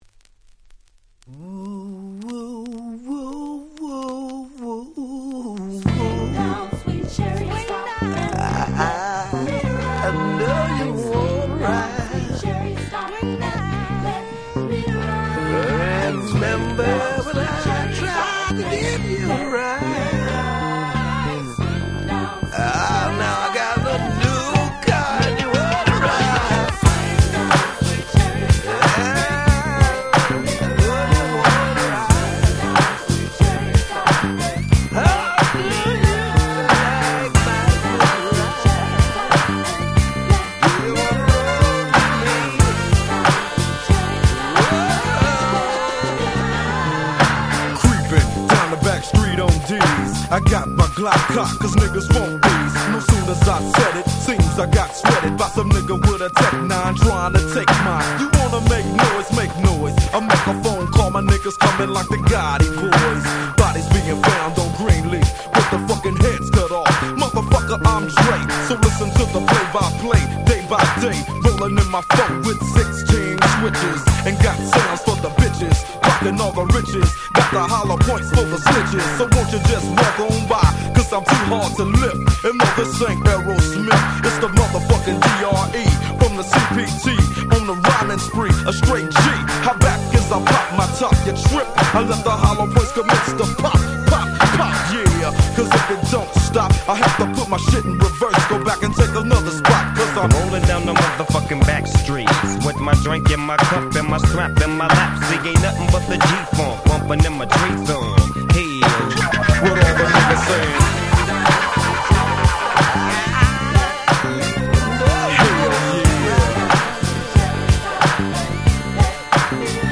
90's West Coast Hip Hop Super Classics !!